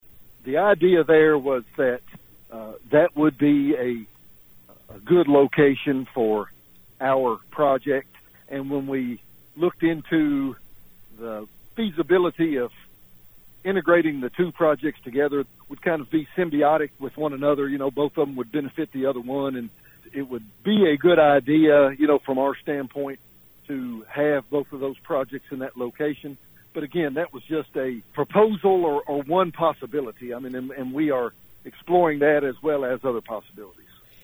Judge Alexander said that they did, however, look into ways they could integrate the two: